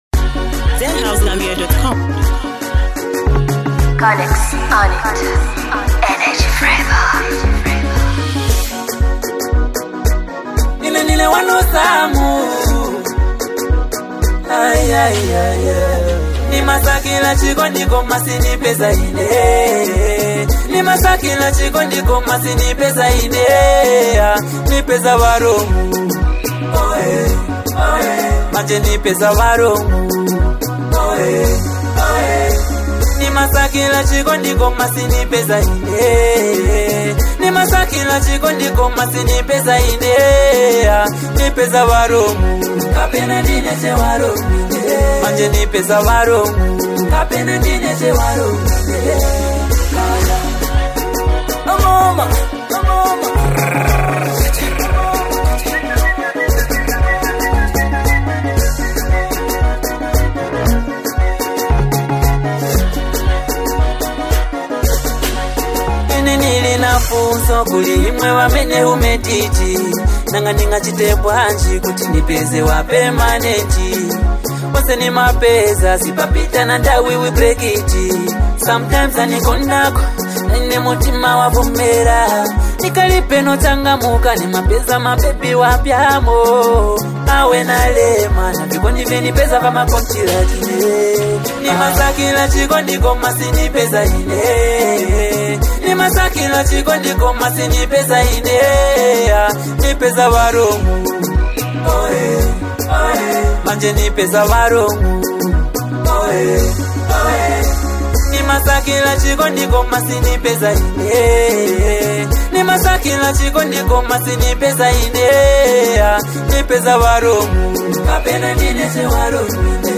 hard-hitting track packed with truth and raw emotion